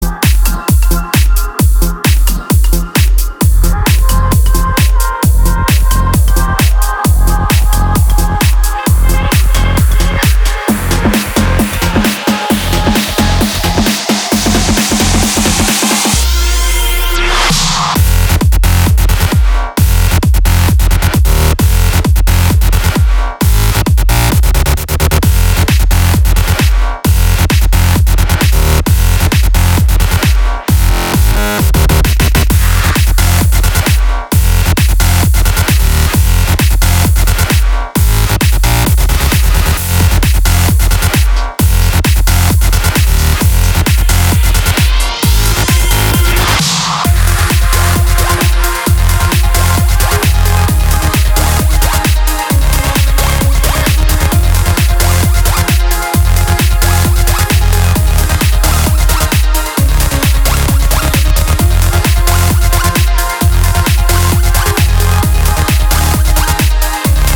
• Качество: 320, Stereo
Trance